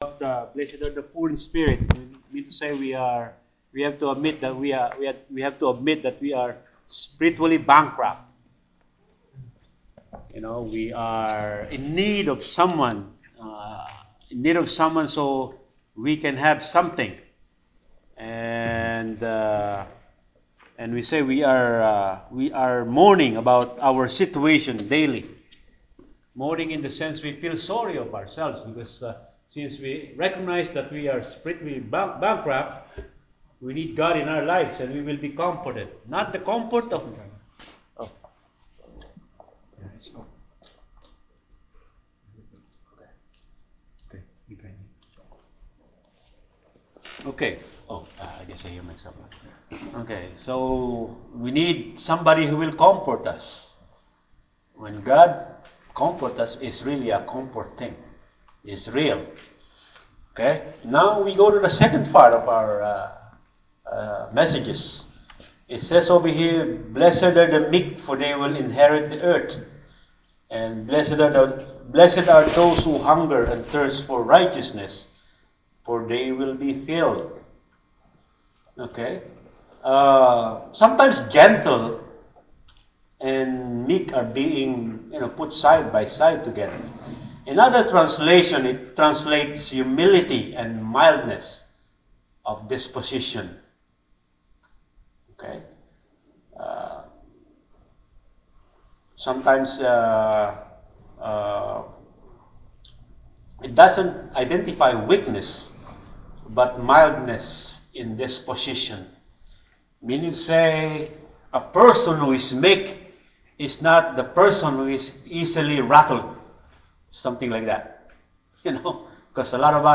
Sermon-11-3-19.mp3